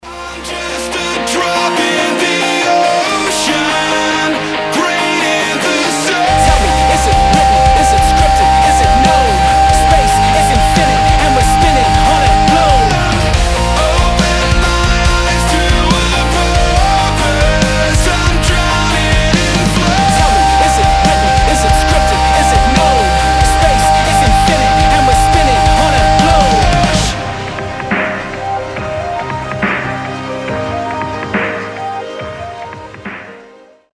• Качество: 320, Stereo
громкие
Драйвовые
Rap-rock
красивый мужской вокал
Rapcore